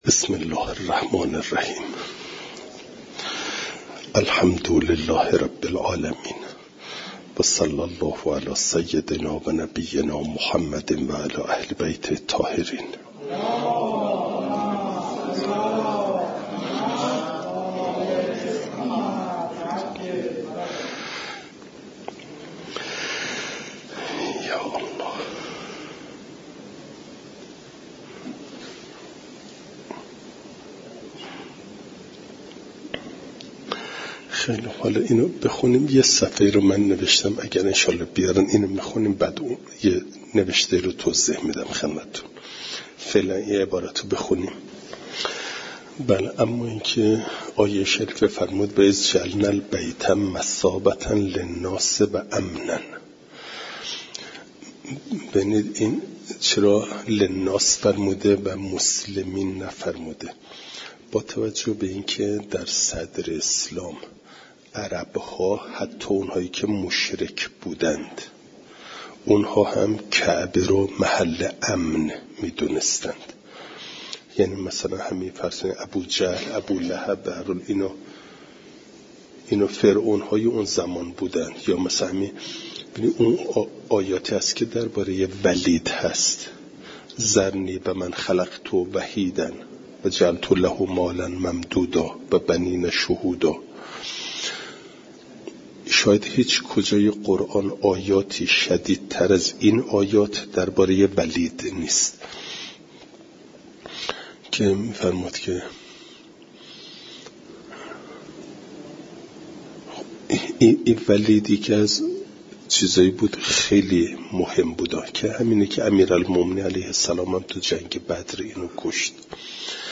درس تفسیر مجمع البیان